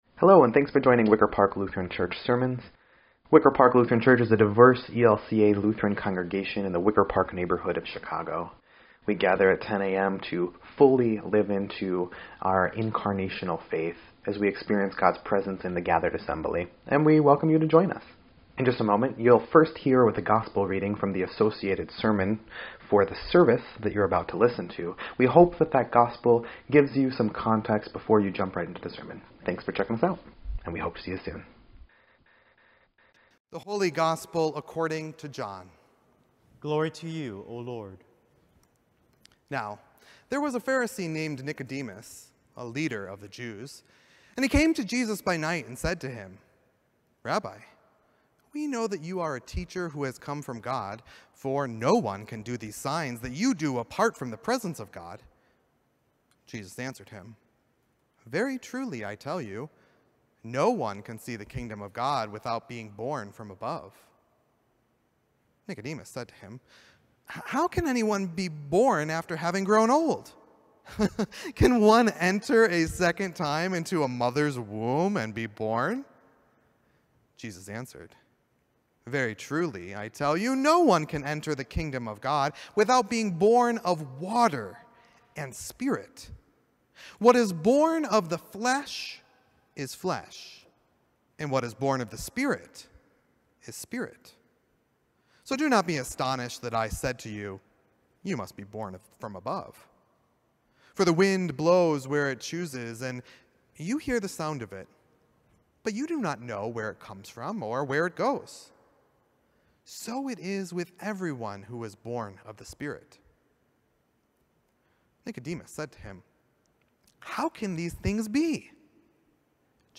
5.30.21-Sermon_EDIT.mp3